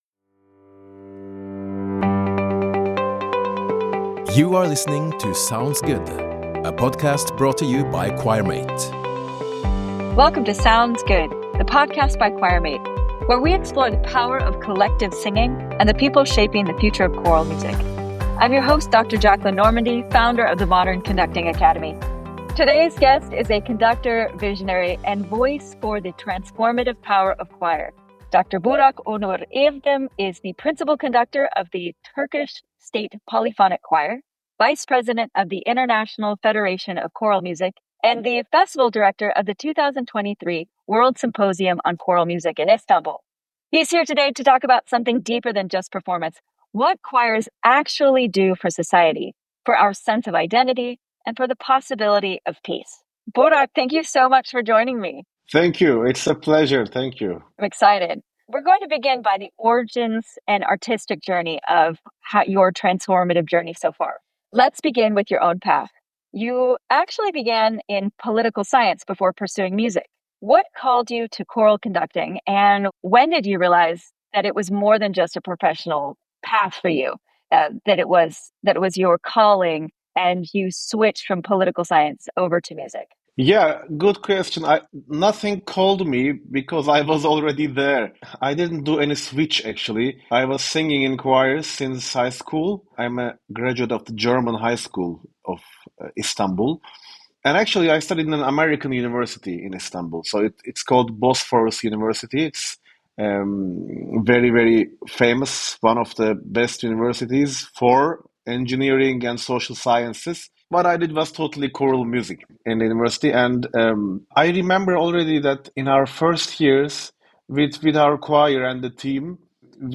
This conversation will resonate with anyone who believes in music's power to heal divisions and build bridges.